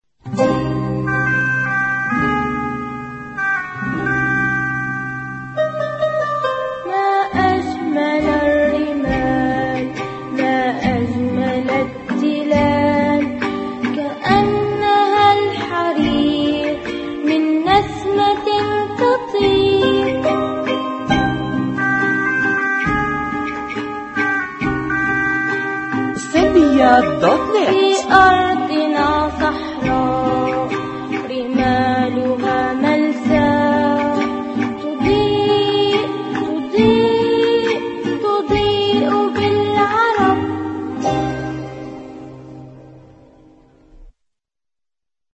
(أغنية) قصص وأناشيد (جحا والحمار)